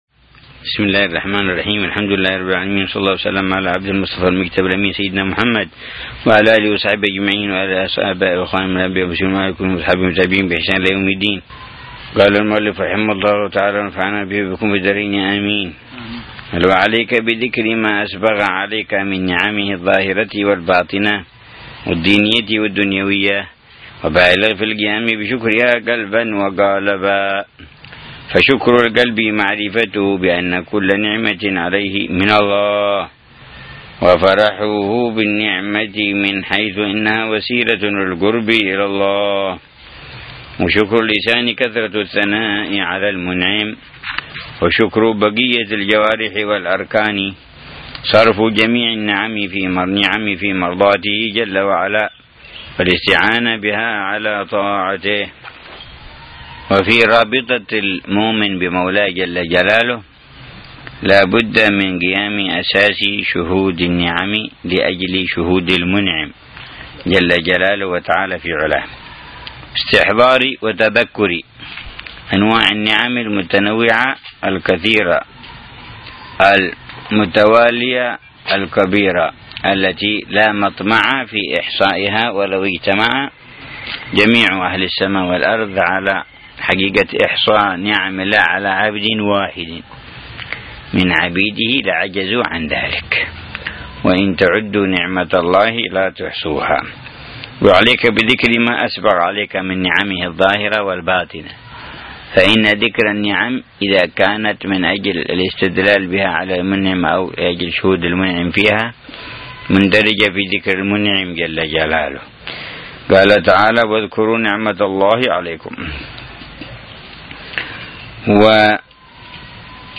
درس أسبوعي يلقيه الحبيب عمر بن حفيظ في كتاب الوصايا النافعة للإمام عبد الله بن علوي الحداد يتحدث عن مسائل مهمة في تزكية النفس وإصلاح القلب وطه